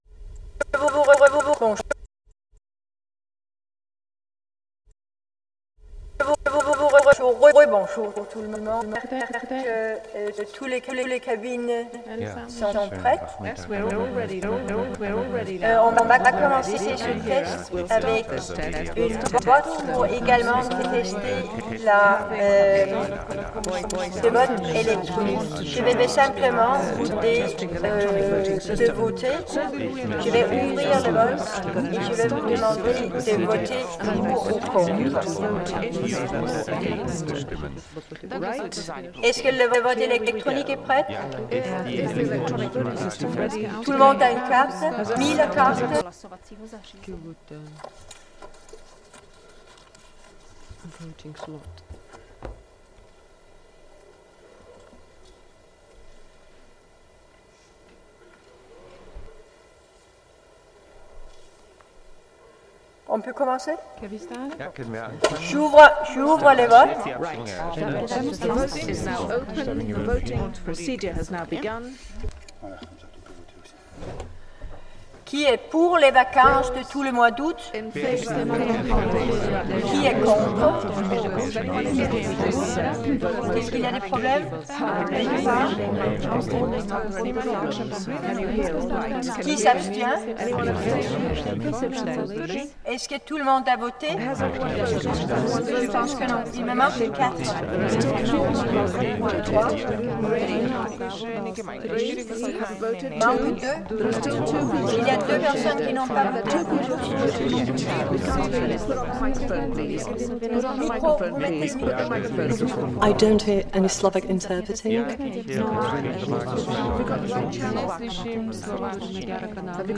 Mutabor – Wer lacht fliegt raus 24 kanal Komposition / Akustische Skulptur „EU positive“, Akademie Der Künste Berlin 2004
Seine Bearbeitung der mikrophonischen Übertragung gibt überraschenden Zwischentönen und ungewohnten akustischen Koalitionen Raum, verwandelt und befreit sie schließlich zu Mikrophonien als einer eigenen Form parlamentarischer Realität.